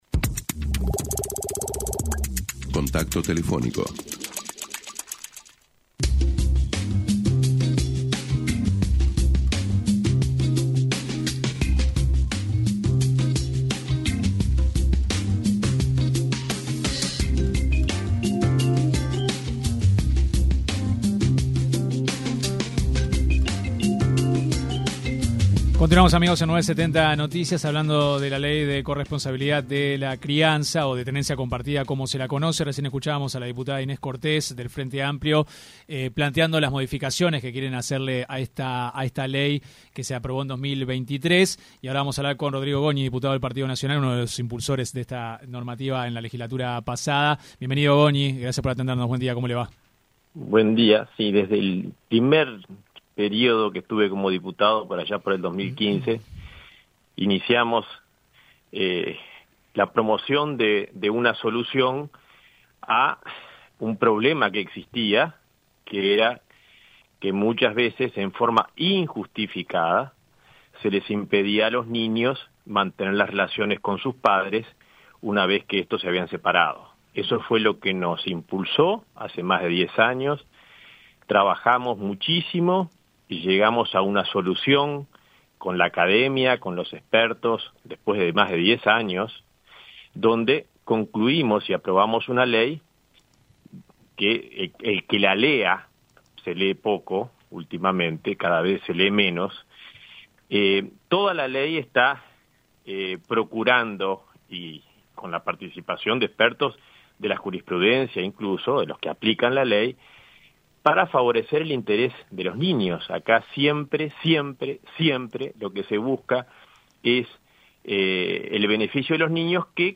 El diputado por el Partido Nacional, Rodrigo Goñi, criticó en diálogo con 970 Noticias, las modificaciones que busca implementar el Frente Amplio en la ley de tenencia compartida.